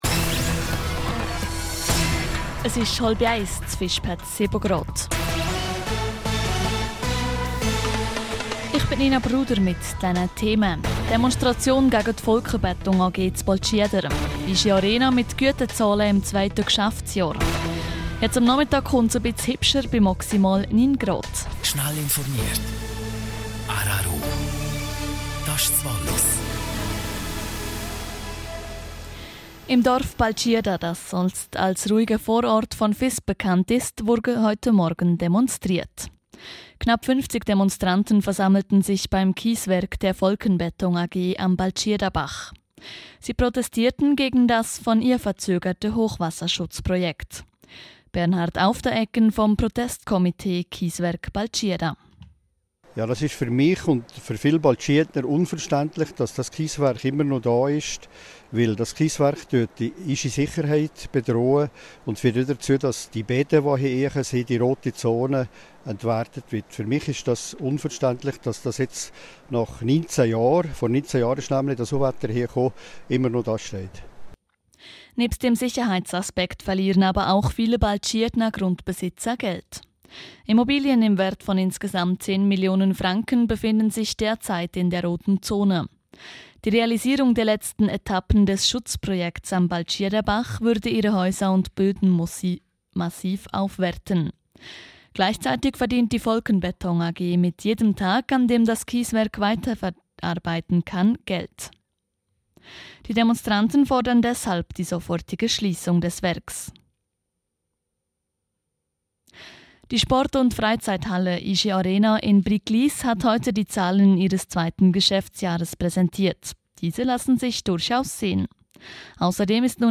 12:30 Uhr Nachrichten (4.73MB)